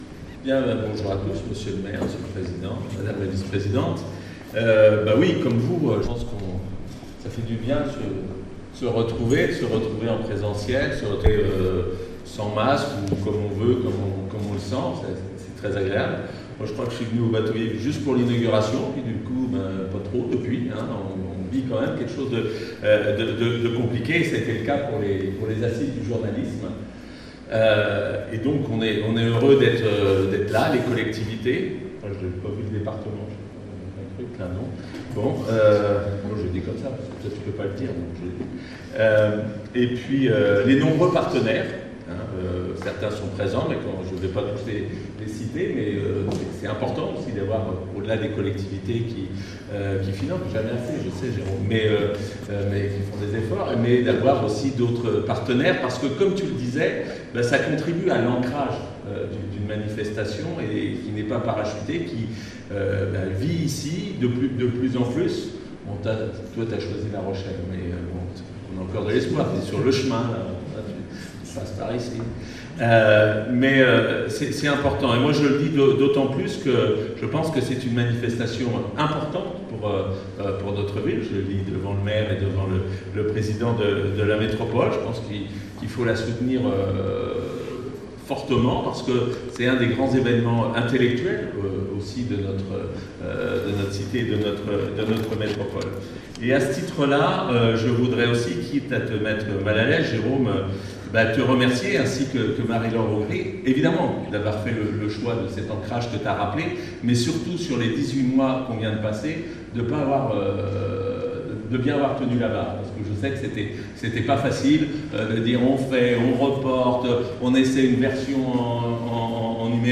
Conférence sur la 14ème édition des Assises Internationales du Journalisme, au Bateau Ivre
Jean-Patrick Gille, représentant de la région Centre-Val de Loire, prend ensuite la parole en insistant sur la présence des collectivités qui participe au financement des Assises. Pour lui, cette manifestation journalistique est importante pour la ville de Tours et de sa métropole.
Jean-Patrick-Gille-représentant-de-la-région-parlant-de-limportance-de-cette-manifestation-journalistique.mp3